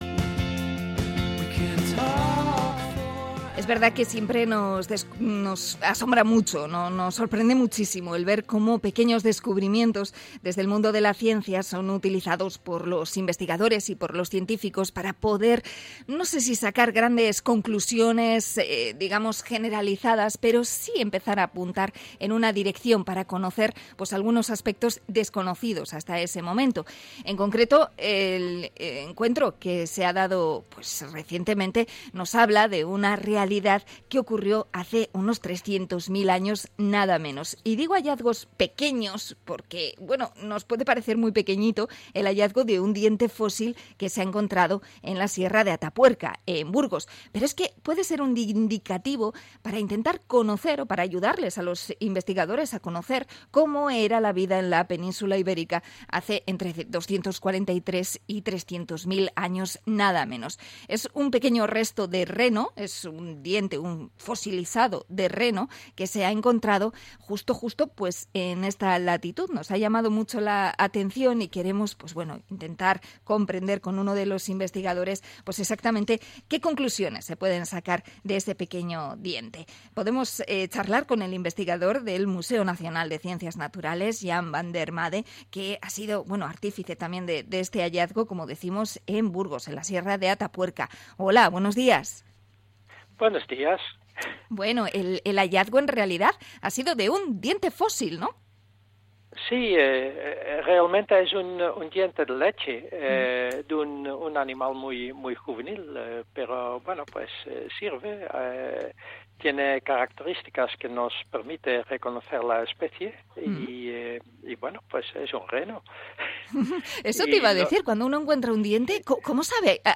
Entrevista con investigador sobre el hallazgo de un diente de reno en Atapuerca